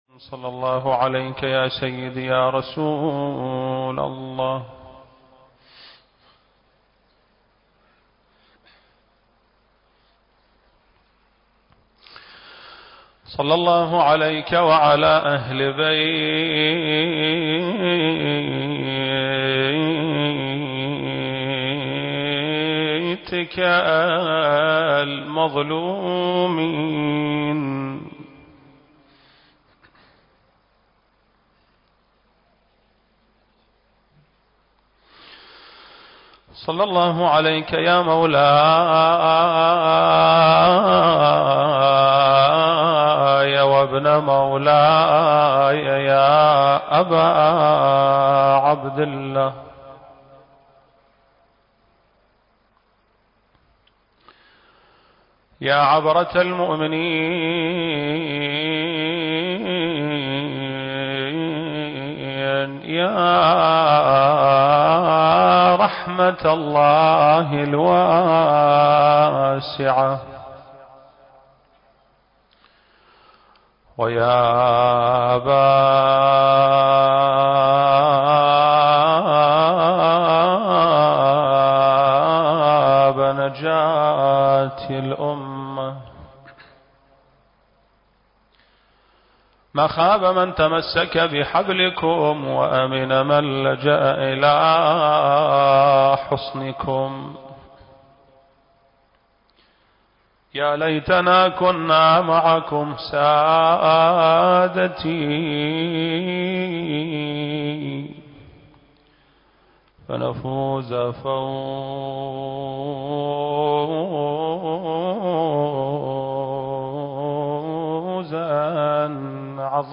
المكان: مسجد آل محمد (صلّى الله عليه وآله وسلم) - البصرة التاريخ: شهر رمضان المبارك - 1442 للهجرة